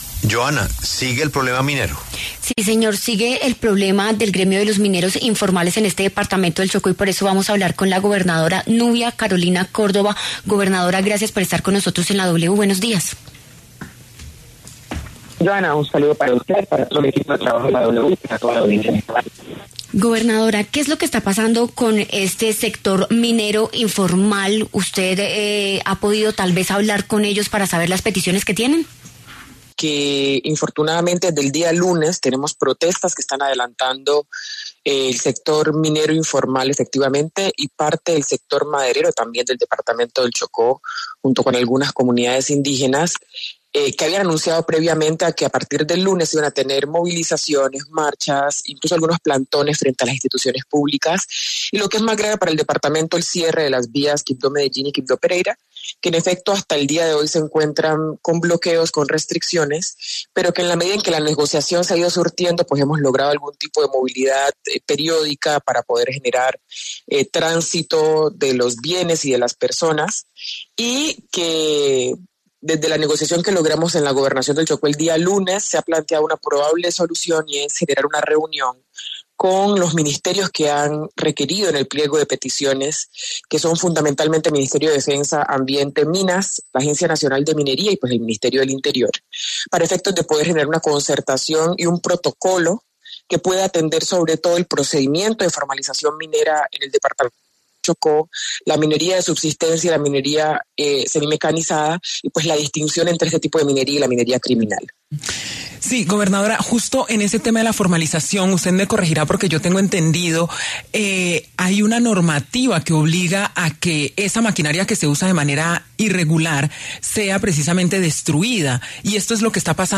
La gobernadora del Chocó, Nubia Carolina Córdoba, pasó por los micrófonos de La W a propósito del paro minero que se realiza en el departamento.